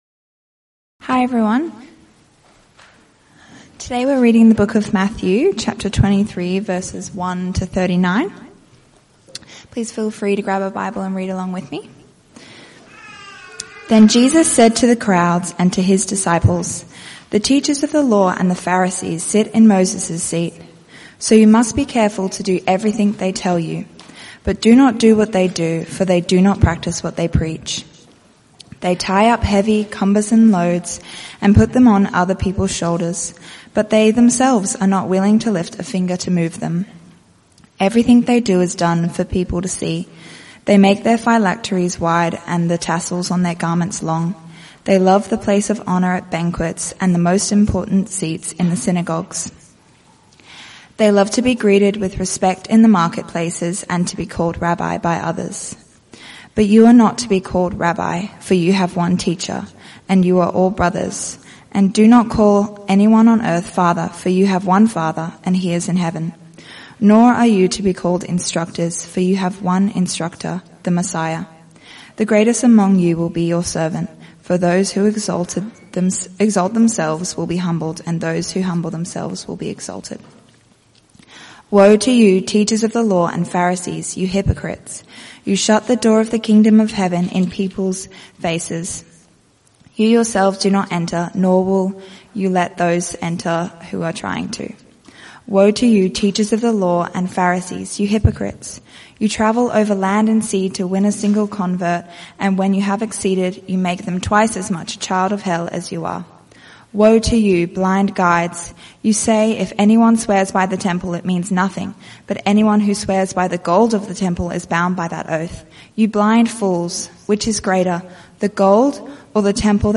CBC Service: 8 Feb 2026 Series
Type: Sermons